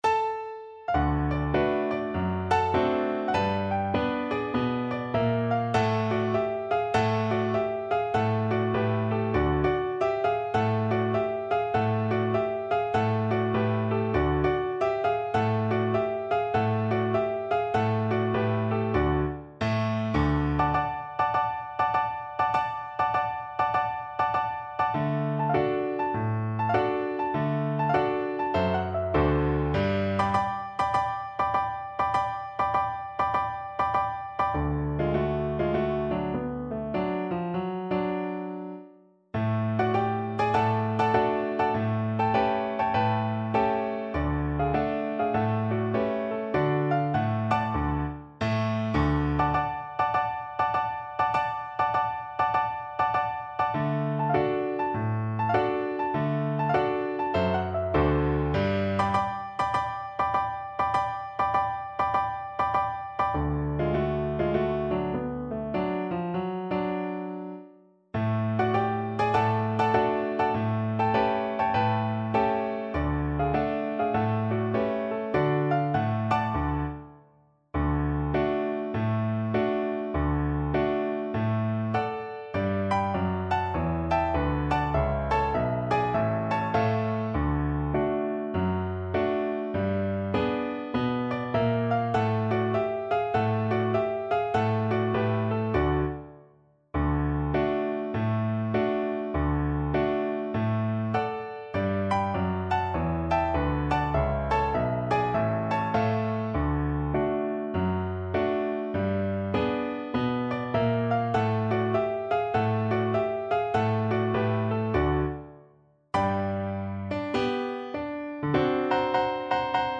Jazz (View more Jazz Viola Music)